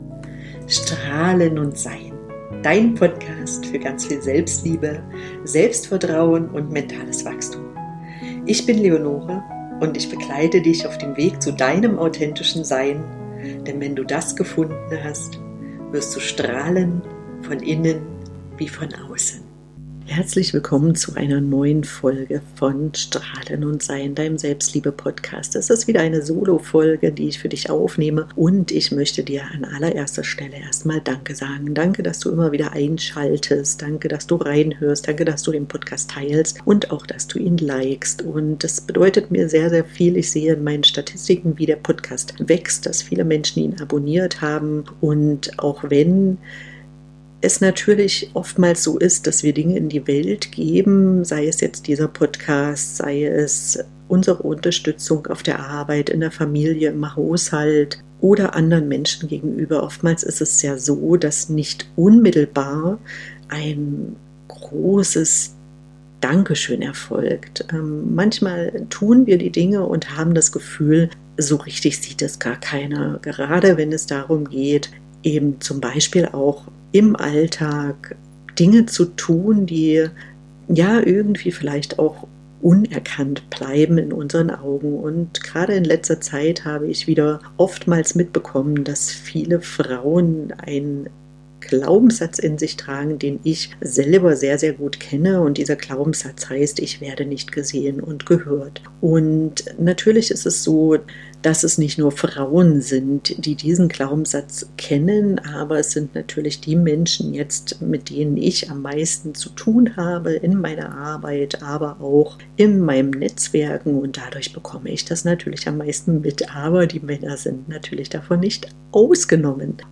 Am Ende führe ich dich durch eine kleine geführte Meditation, damit du wieder in Verbindung mit dir selbst kommst und erlebst: Ich bin wichtig.